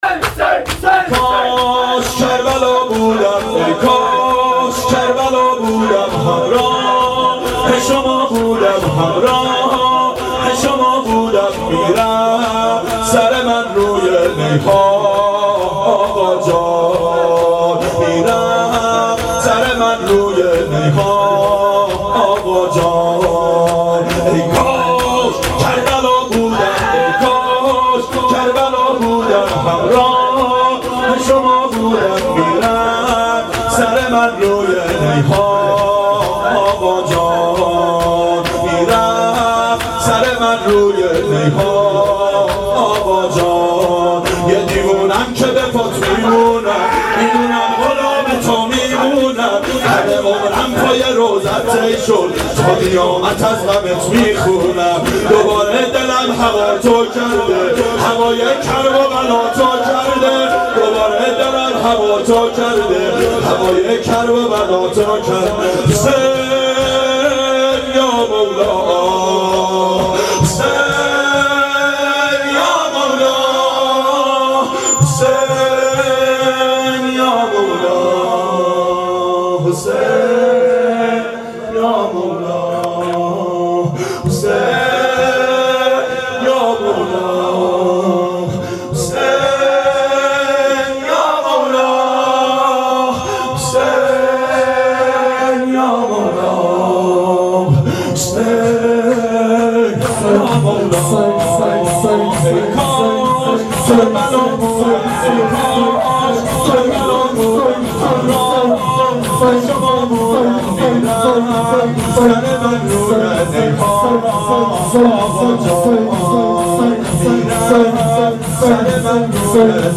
ای کاش حرمت بودم - شور
• شب اربعین 92 هیأت عاشقان اباالفضل علیه السلام منارجنبان